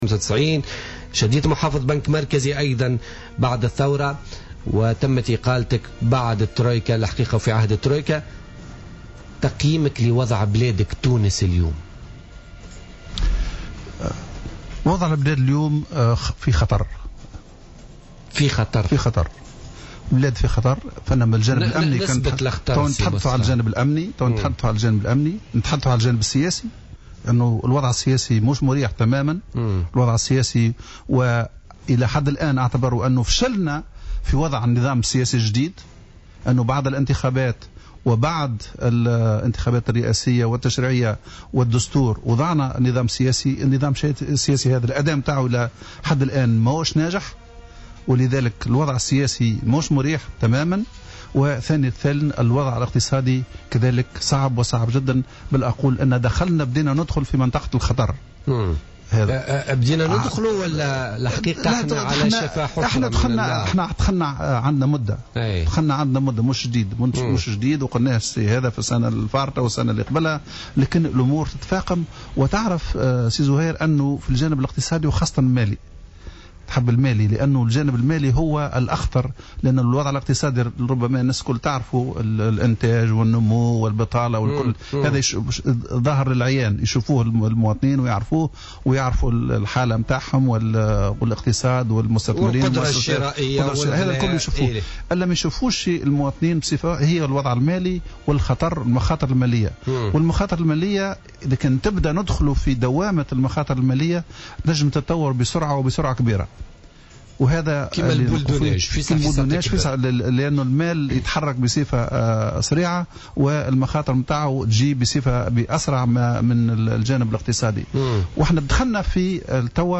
أكد محافظ البنك المركزي السابق مصطفى كمال النابلي ضيف بوليتيكا اليوم الثلاثاء 1 ديسمبر 2015 أن تونس دخلت مرحلة دوامة المخاطر المالية مشيرا إلى أن الإستثمار في انحدار والإنتاج في تراجع مطرد وهو ماسيساهم في اثقال كاهل المالية التونسية على حد قوله.